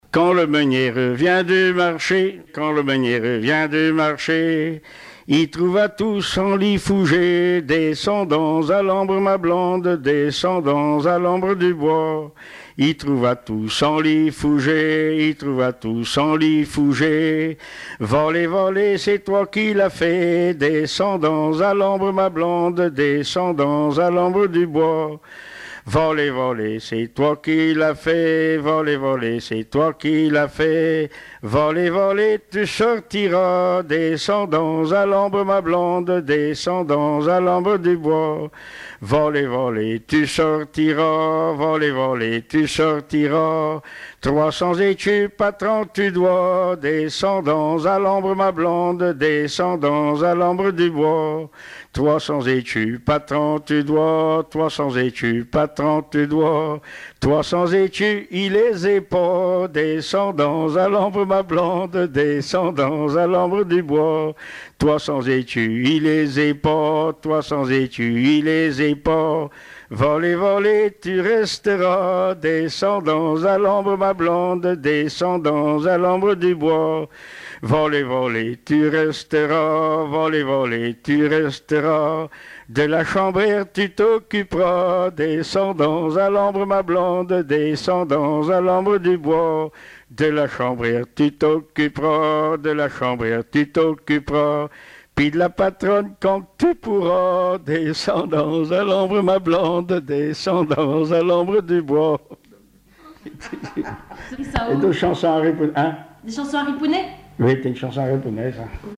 Enquête Arexcpo en Vendée-C.C. Essarts
Témoignages et chansons traditionnelles et populaires
Pièce musicale inédite